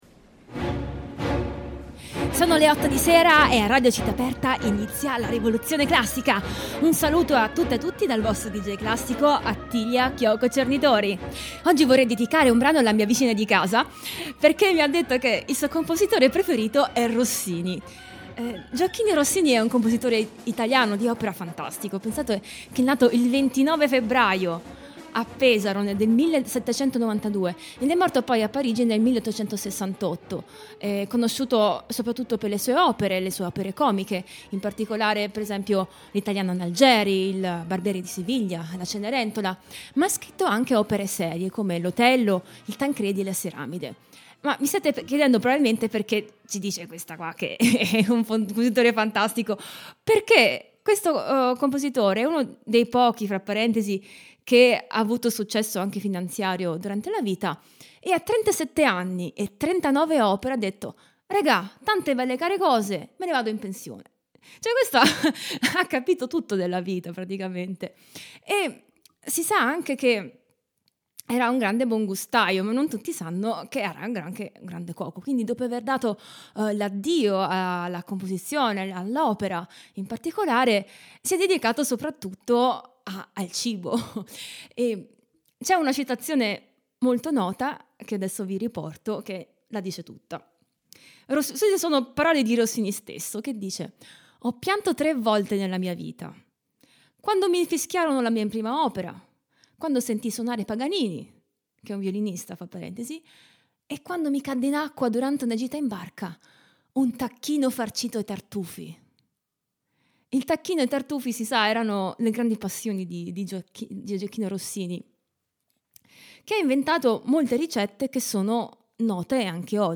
sassofono soprano
sassofono contralto
sassofono tenore
sassofono baritono
Suite Popolare per Quartetto di Sax: Ballabile e Tango